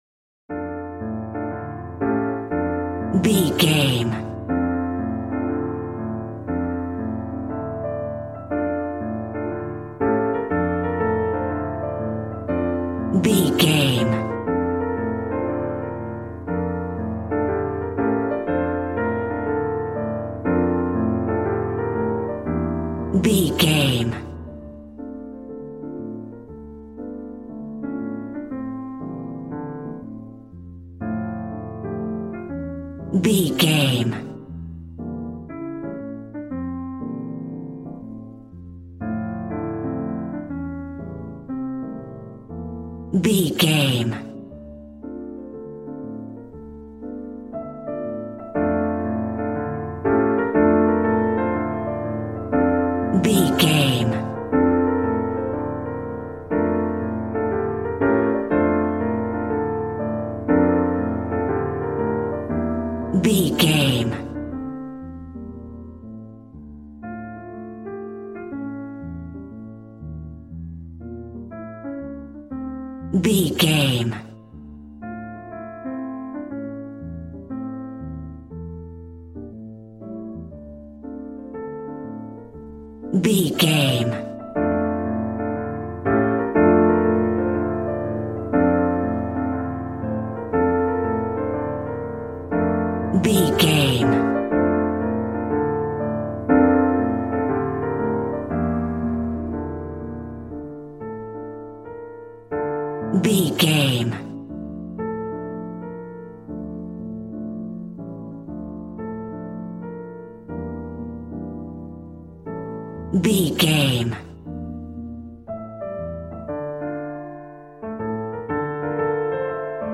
Smooth jazz piano mixed with jazz bass and cool jazz drums.,
Aeolian/Minor
piano